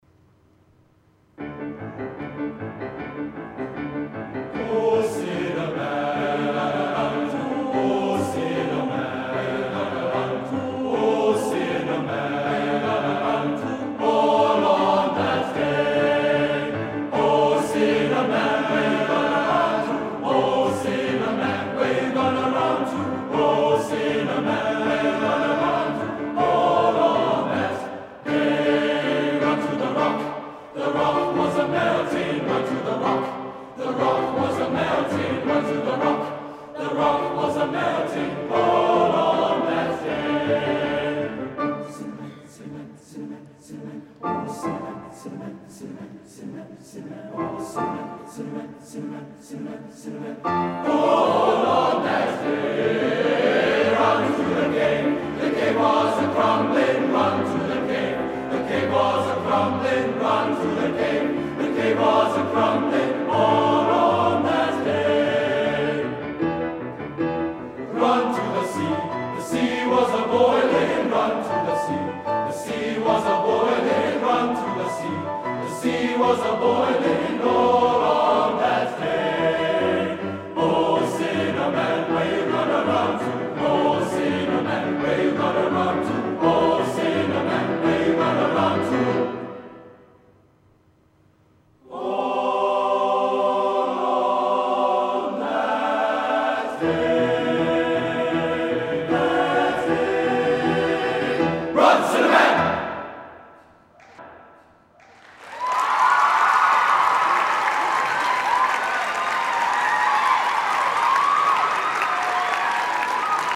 Recordings from The Big Sing National Final.
Voicemale Westlake Boys' High School Oh, Sinner Man Loading the player ...